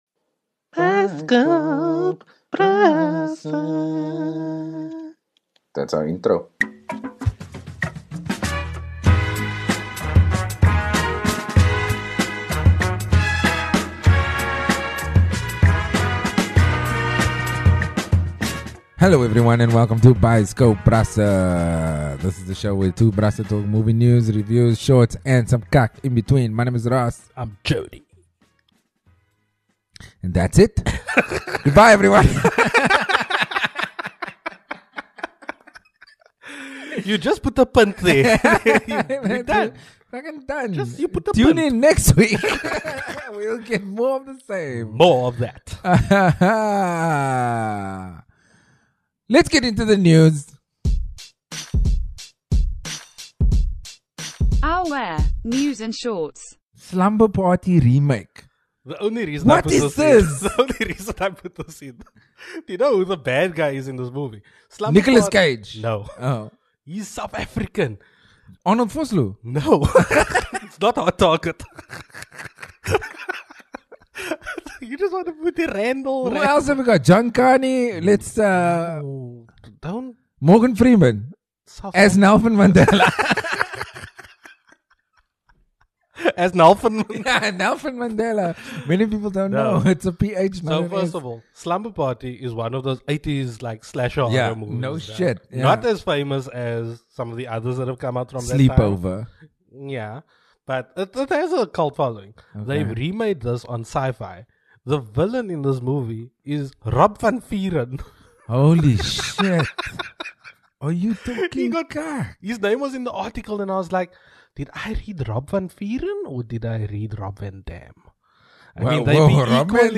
It's time for another Bioscope Brasse Spooktacureffic horror movie review special!